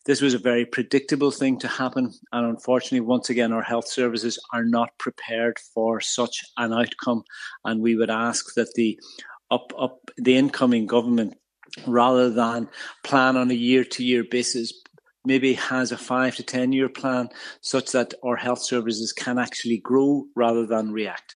Donegal doctor calls for long-term plan to help health service cope with flu surge